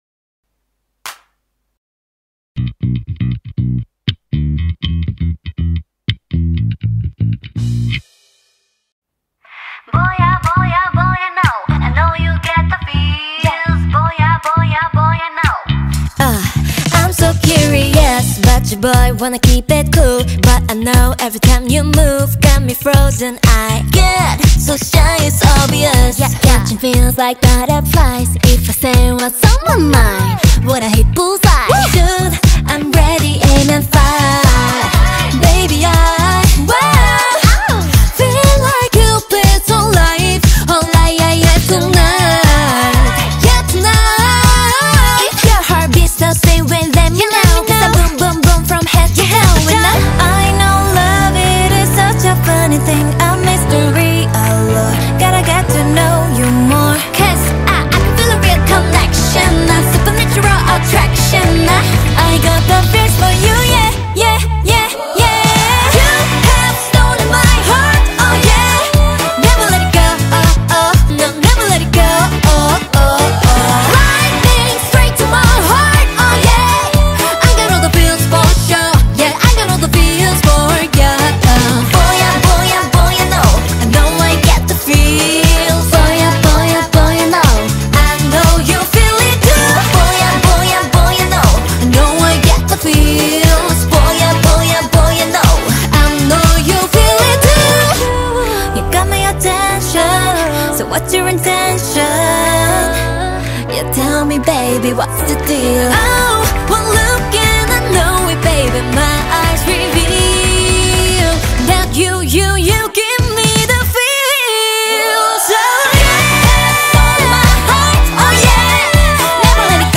BPM120
Audio QualityCut From Video